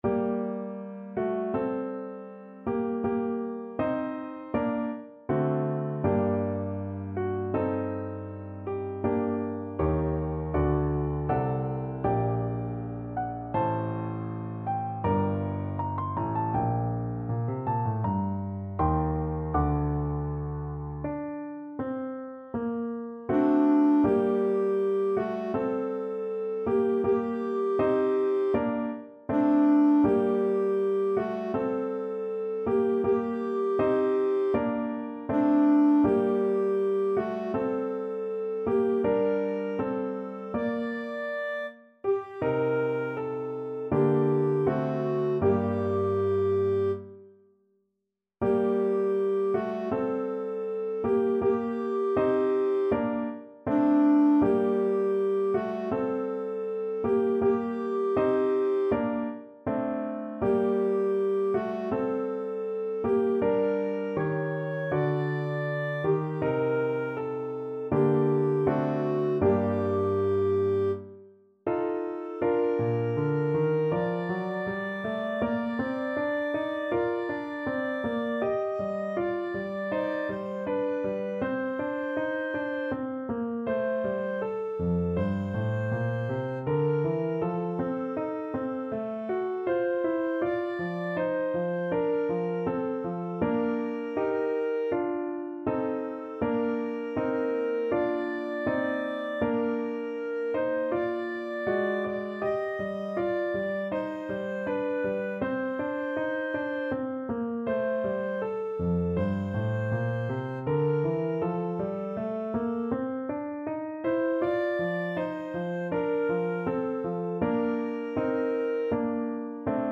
Piano Playalong MP3
Tempo Marking: Andante =c.80 Score Key: G minor (Sounding Pitch)
Time Signature: 4/4
Instrument: Clarinet